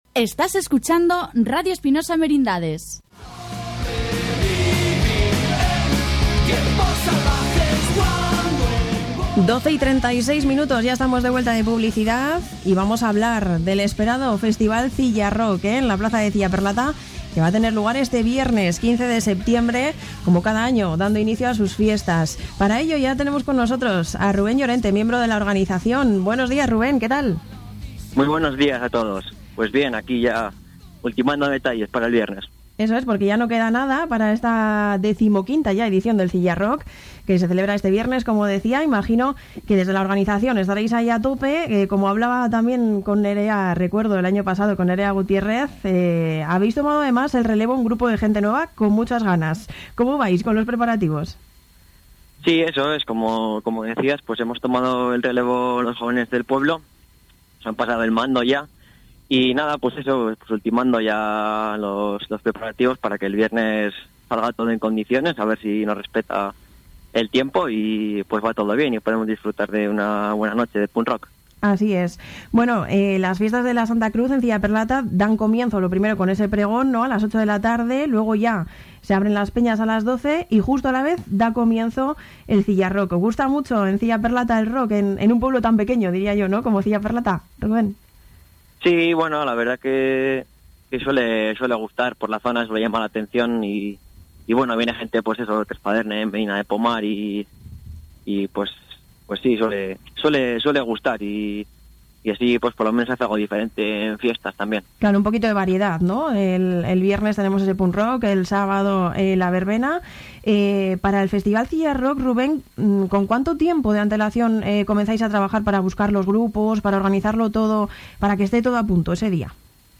Os dejamos el audio de la entrevista de presentación del Cillarock de este año en Radio Espinosa Merindades